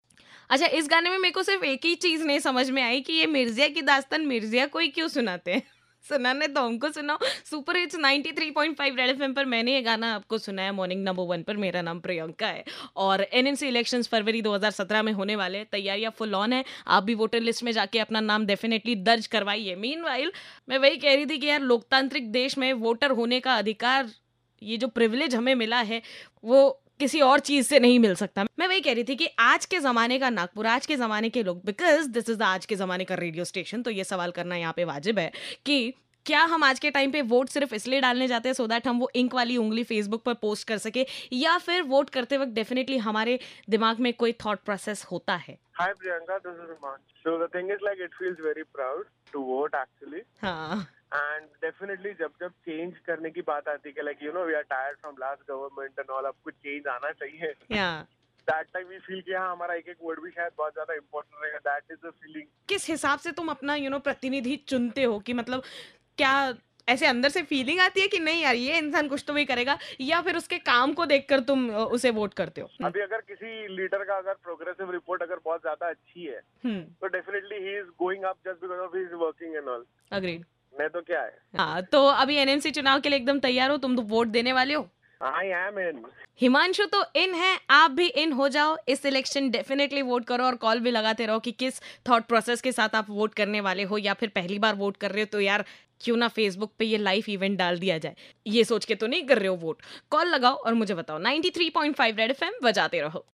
TALKING ABOUT ELECTIONS WITH CALLERS